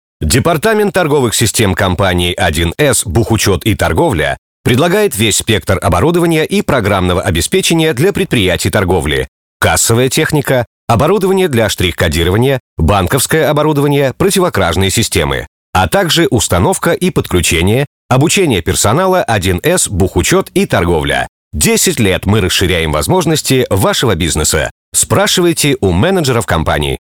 Professioneller russischer Sprecher für TV / Rundfunk / Industrie. Professionell voice over artist from Russia.
Sprechprobe: Werbung (Muttersprache):